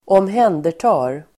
Ladda ner uttalet
Uttal: [²åmh'en:der_ta:r]